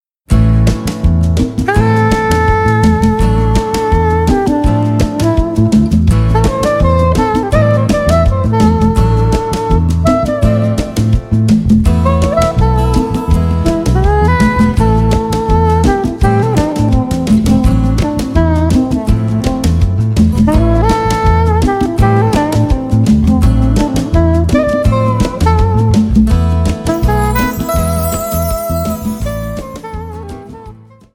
• CD with exercise music